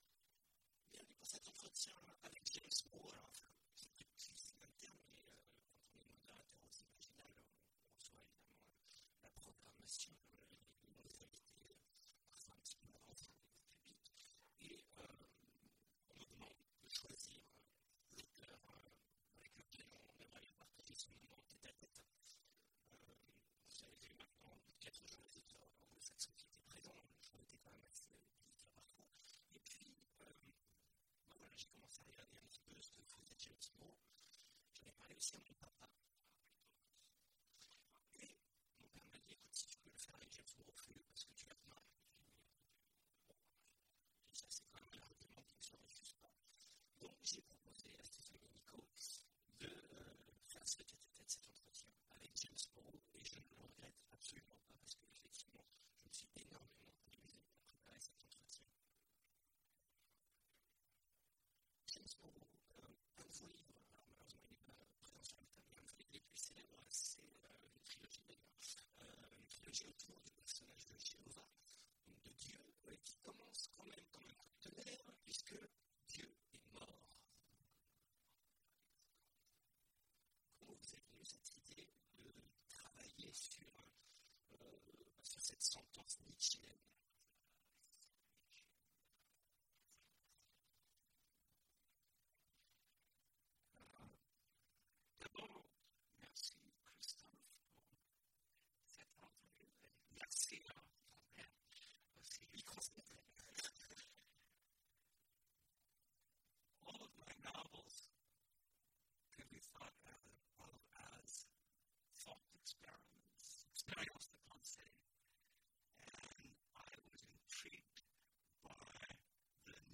Imaginales 2016 : Entretien avec... James Morrow
James Morrow Télécharger le MP3 à lire aussi James Morrow Genres / Mots-clés Rencontre avec un auteur Conférence Partager cet article